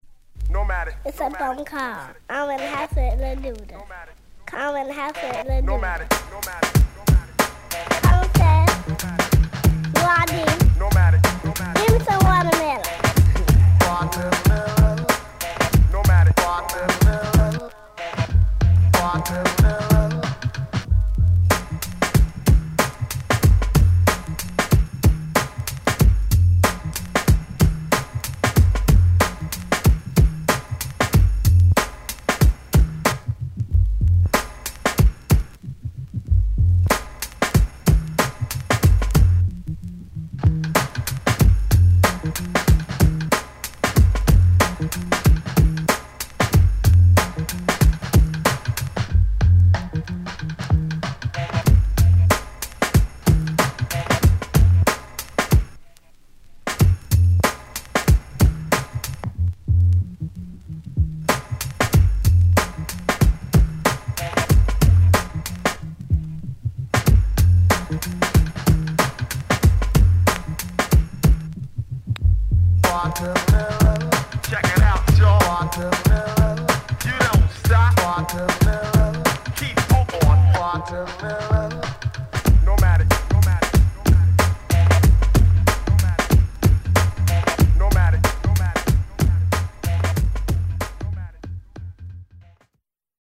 その中でも本盤は音圧が一番高い2LP、45回転です。